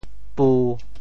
调: 低
国际音标 [pu]